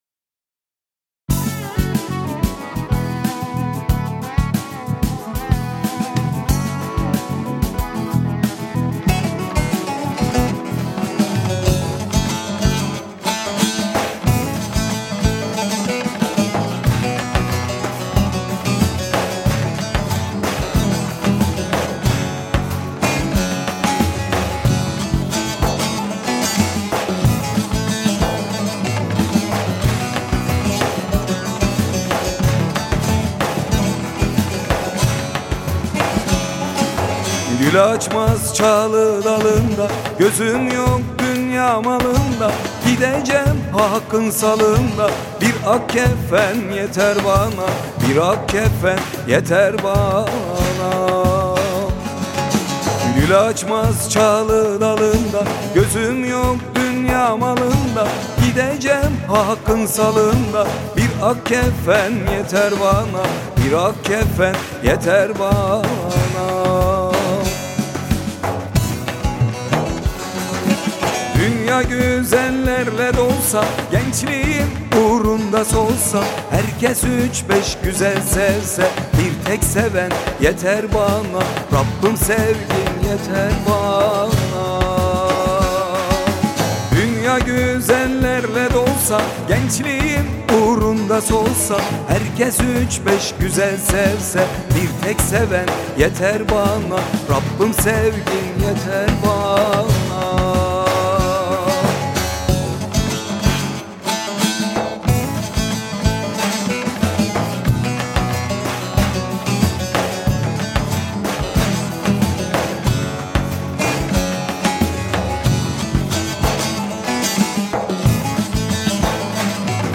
Pop Fantazi